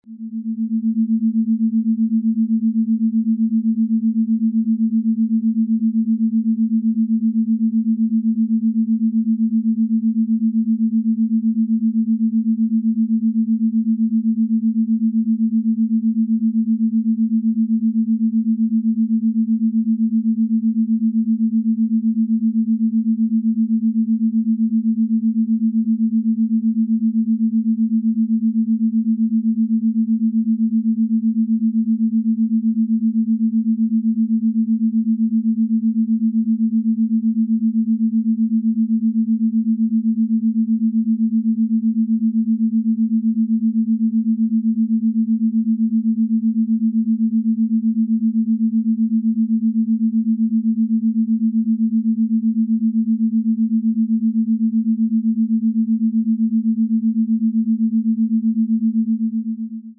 The audio frequency samples below were recorded with the app.
Binaural beat.
220 Hz & 227.83 Hz : 7.83 Hz beat frequency (Alpha).